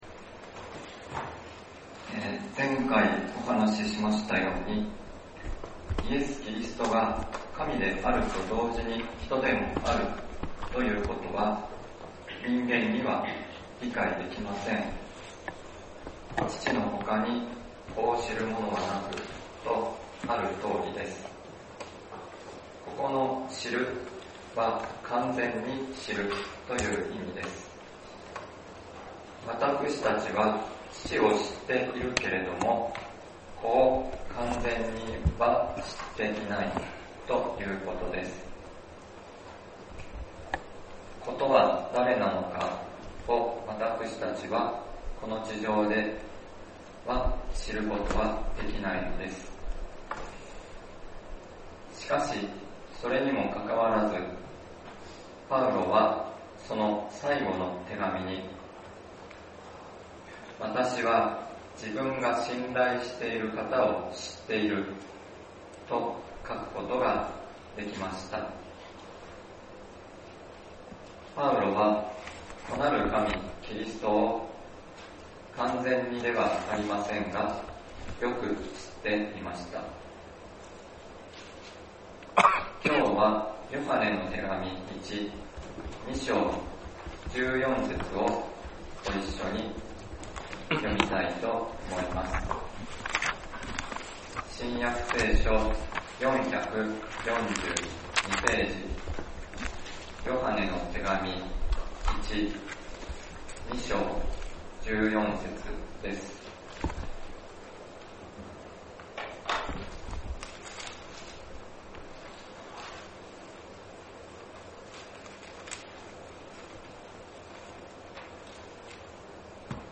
聖なる聖なる聖なるかな 三つにいまして一つなる 神の御名をば 朝まだき 起き出でこそ ほめまつれ♪ 先週，東京集会で行われた礼拝で録音された建徳です。